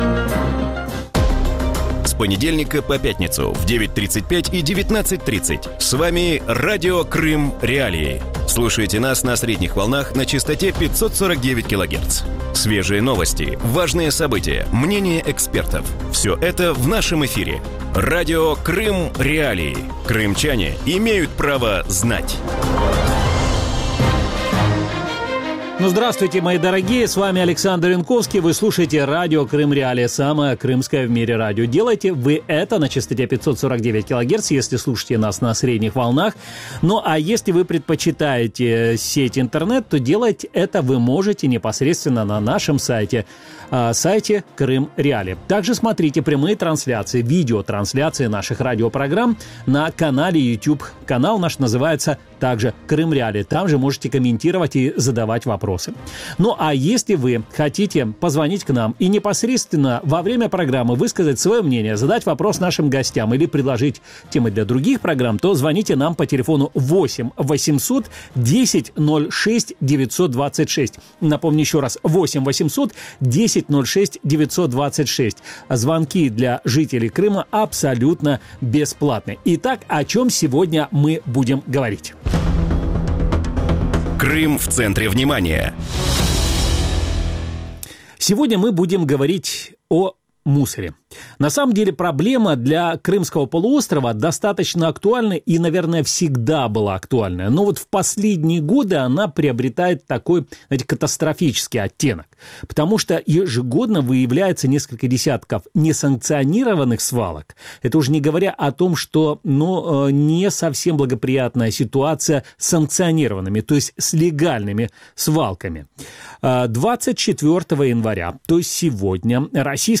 У вечірньому ефірі Радіо Крим.Реалії говорять про проблему вивозу сміття у Криму. Як російська влада півострова справляється з утилізацією твердих побутових відходів, як в Криму функціонують полігони ТПВ та чи є надія на створення сучасної системи переробки сміття?